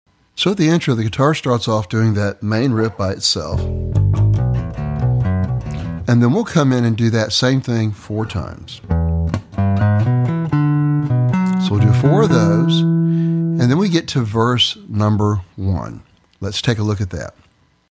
(Bass Guitar)
Lesson Sample
For Bass Guitar.